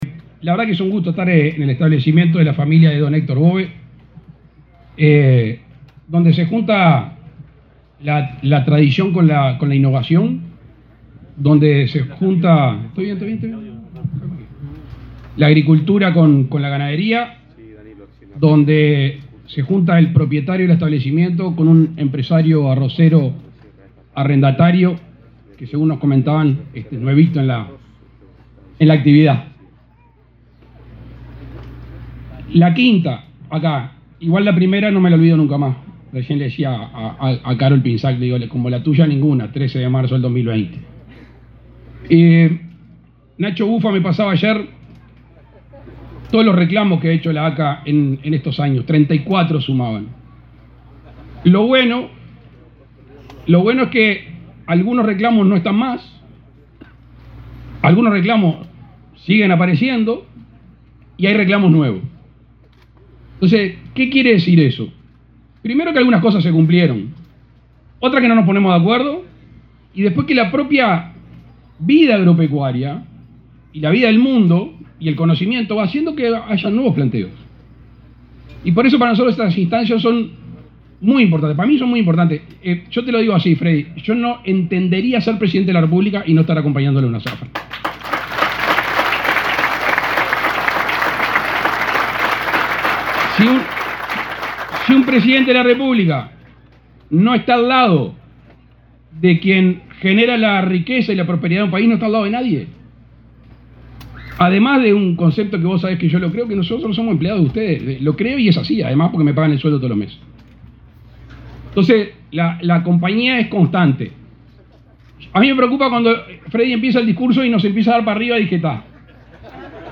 Palabras del presidente Luis Lacalle Pou
El presidente de la República, Luis Lacalle Pou, participó, este viernes 8 en Tacuarembó, en la inauguración de la cosecha del arroz.